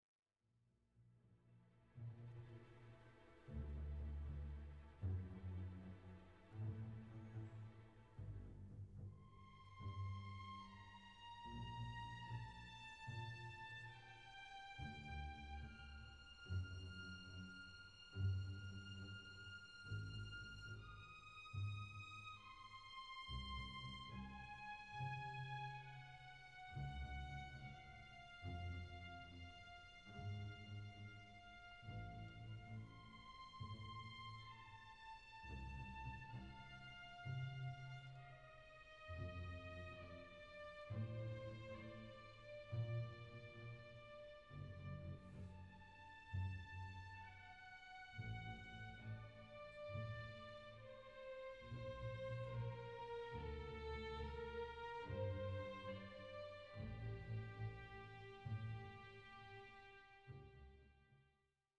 long bel canto over walking bass